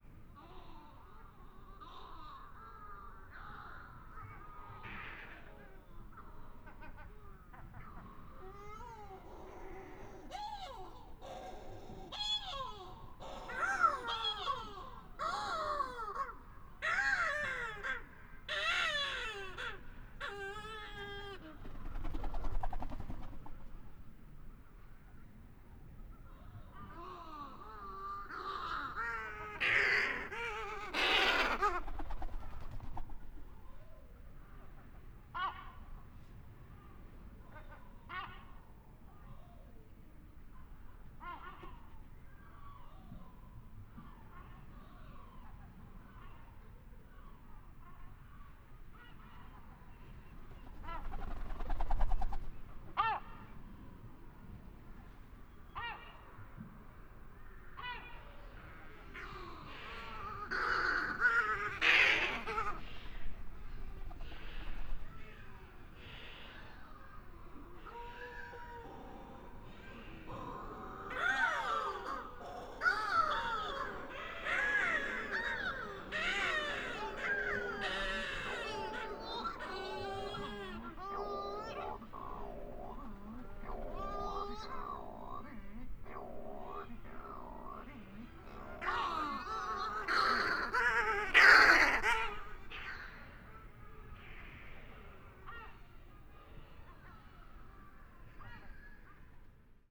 Balearic Shearwater Puffinus mauretanicus (red dots).
Recordings: Mallorca, Balearic Islands.
1-51-Balearic-Shearwater.wav